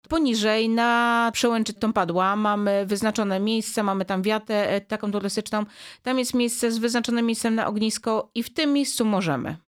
Zbliżający się Sylwester, rosnąca popularność górskich wędrówek, potrzeba ochrony przyrody oraz 5. edycja akcji „Choinka dla Życia” – to główne tematy rozmowy w studiu Radia Rodzina.